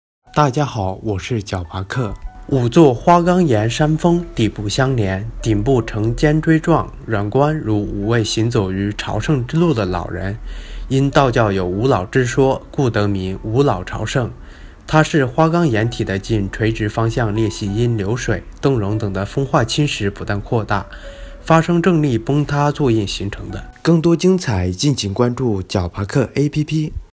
五老朝圣----- 呼呼呼～～～ 解说词: 五座花岗岩山峰底部相连，顶部呈尖锥状，远观如五位行走于朝圣之路的老人，因道教有五老之说，故得名“五老朝圣”。